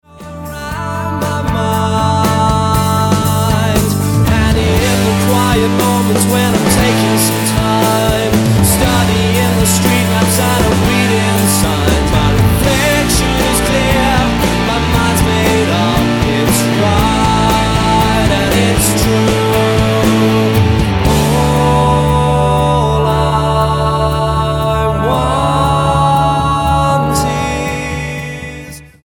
British rock three piece
10187 Style: Rock Approach